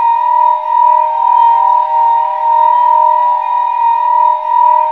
A#3 WHIST02R.wav